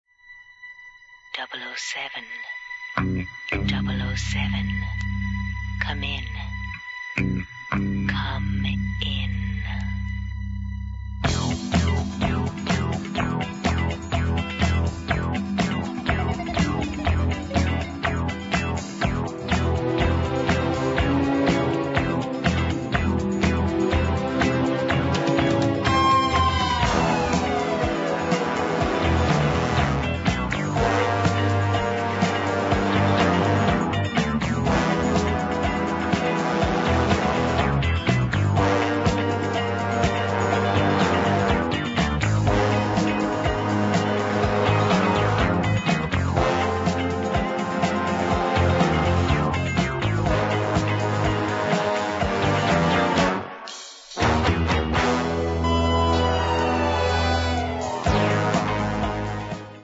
Dancefloor dynamite and instantly recognisable.